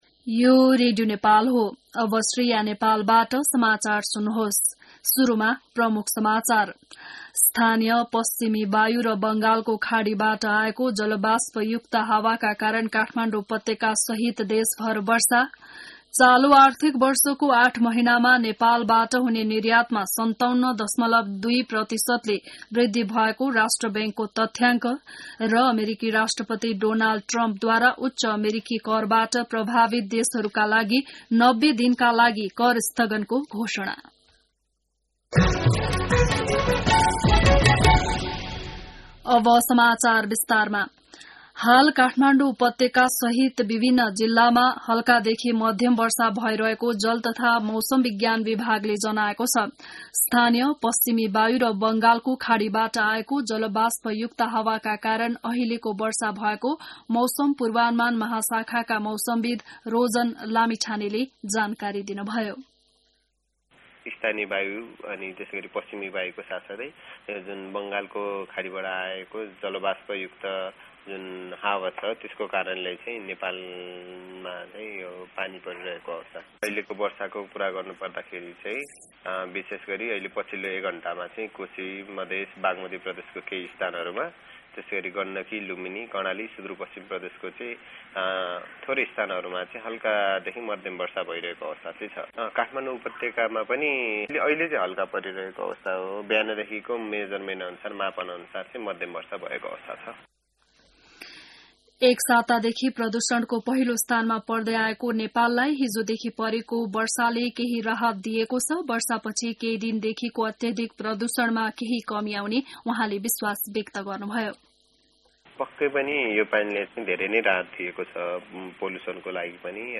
बिहान ९ बजेको नेपाली समाचार : २८ चैत , २०८१